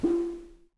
timpsnare_ppp.wav